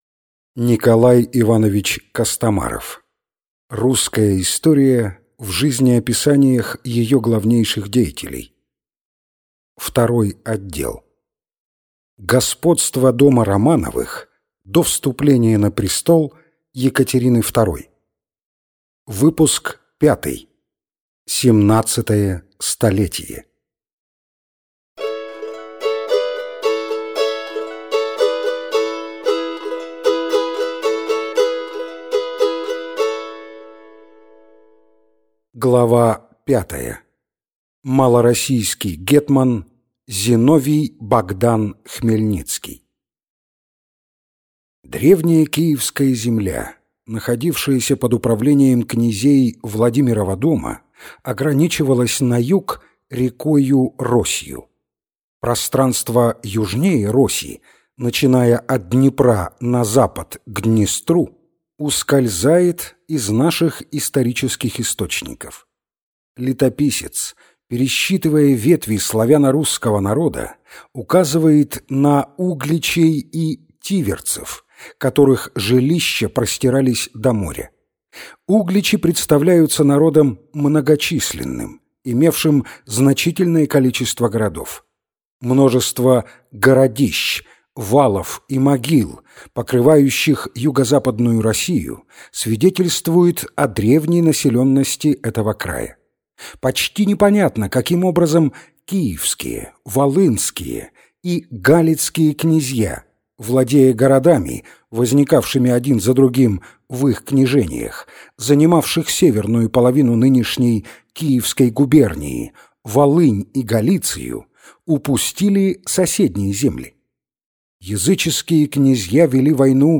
Аудиокнига Русская история в жизнеописаниях. Выпуск 5 | Библиотека аудиокниг